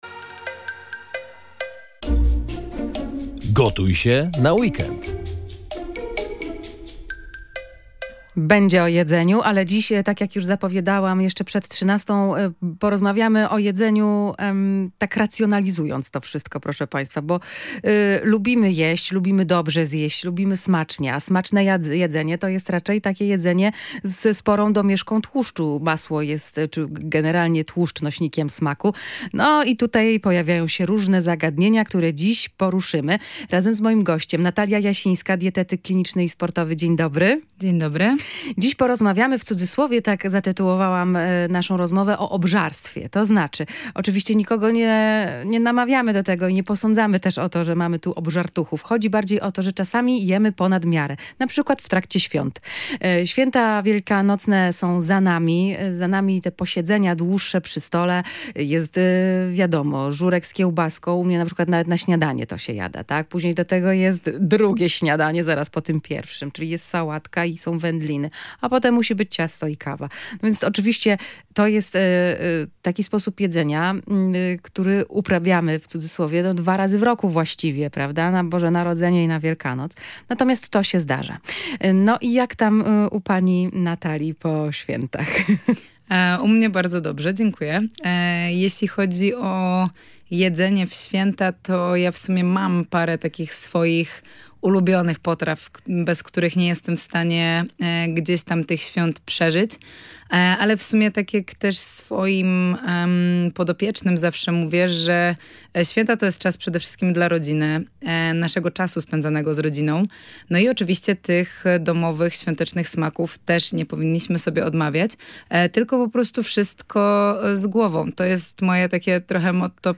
dietetyk kliniczny i sportowy.